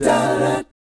1-CMI7.wav